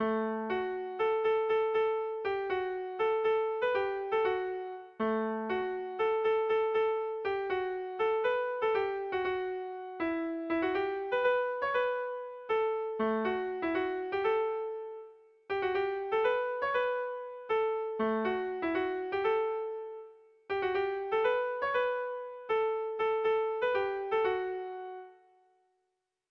Bertso melodies - View details   To know more about this section
Sentimenduzkoa
Hamarreko txikia (hg) / Bost puntuko txikia (ip)
A1A2B1B2B2